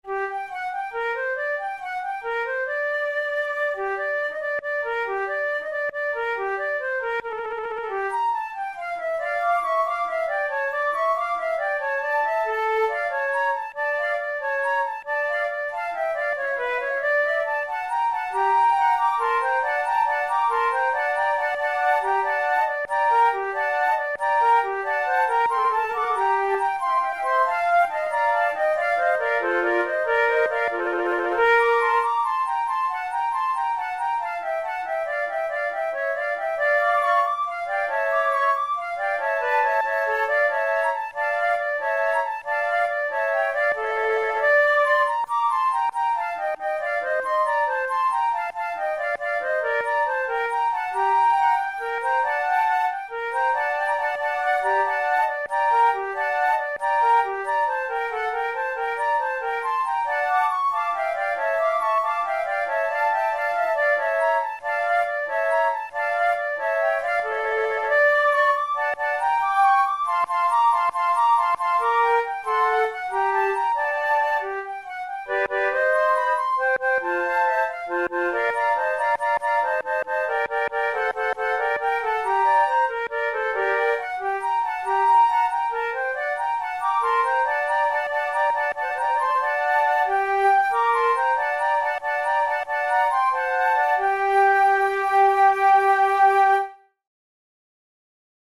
InstrumentationFlute trio
KeyG minor
Time signature12/8
Tempo92 BPM
Baroque, Sonatas, Written for Flute